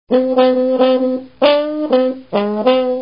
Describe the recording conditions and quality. But in our examples we use samples with low quality because they are more fast in downloading.